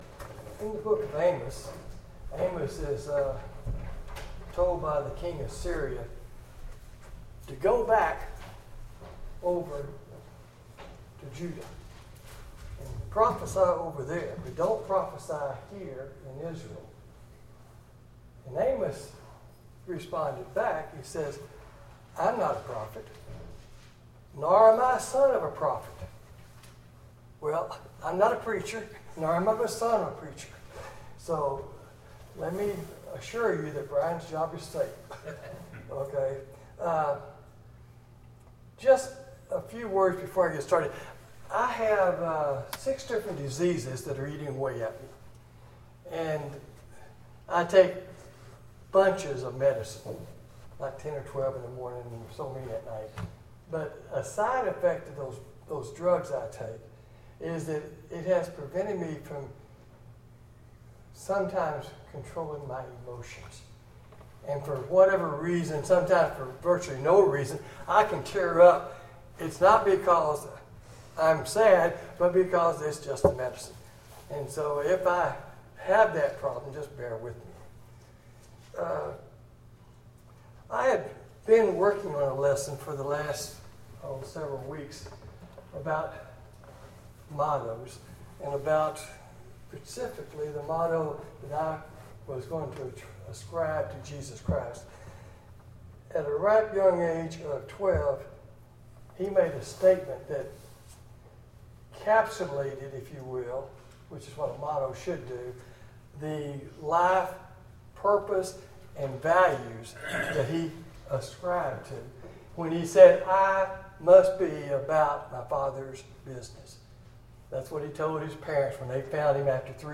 Bible Text: Micah 6:8 | Preacher